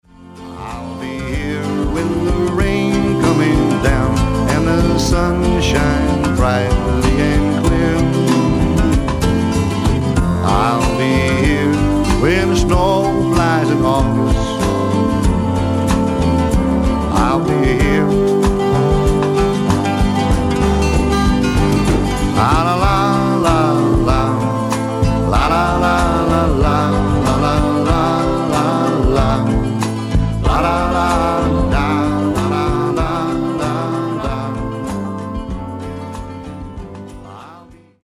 ヴォーカル、12弦ギター
ベース
パーカッション
ピアノ